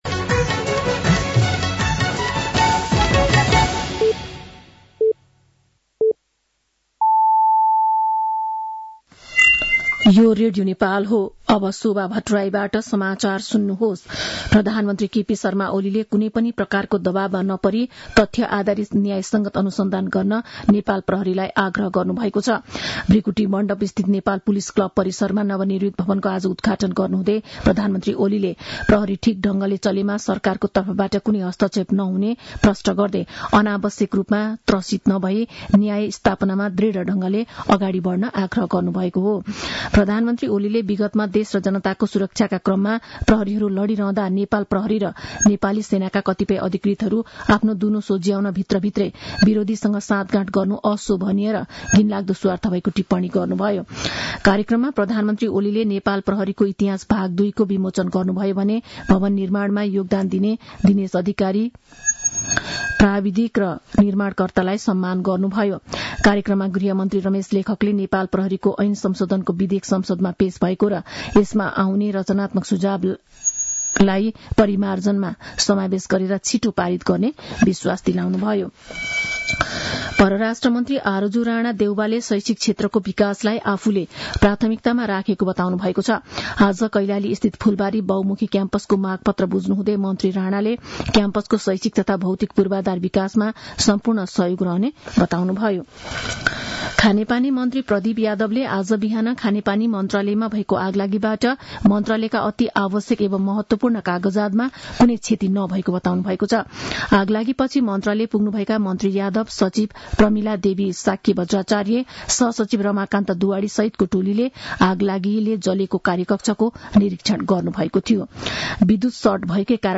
साँझ ५ बजेको नेपाली समाचार : २७ माघ , २०८१
5-pm-news.mp3